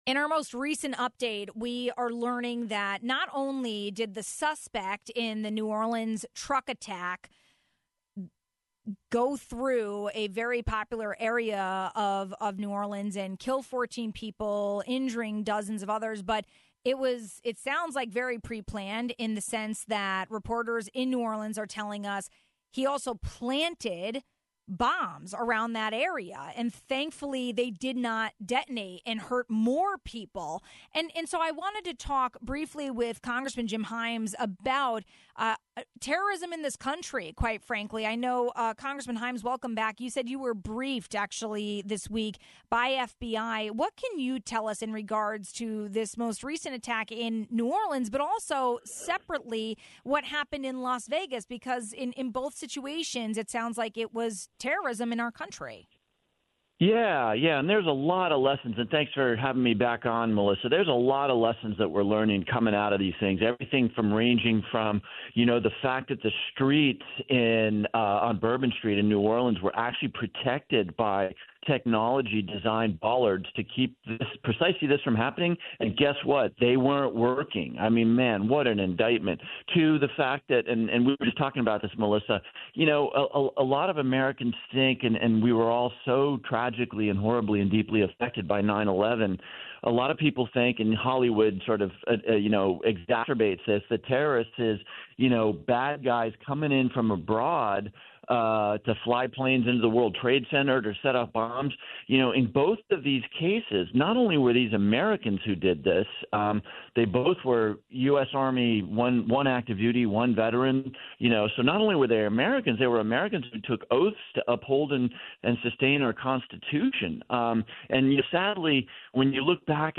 But this most recent case of terrorism in America begs the question: how could this have been detected and prevented? We asked Congressman Jim Himes, recently briefed by the FBI about this case and the reality of terrorism in our country.